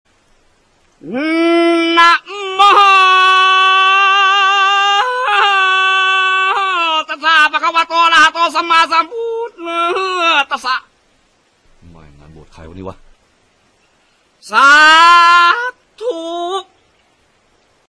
หมวดหมู่: เสียงมีมไทย